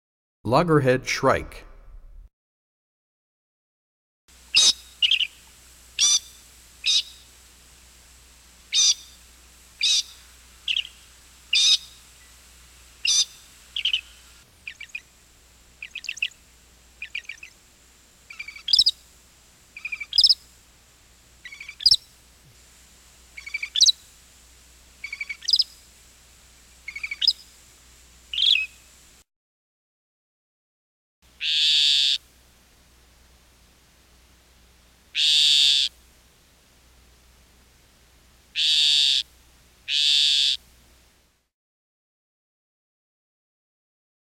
55 Loggerhead Shrike.mp3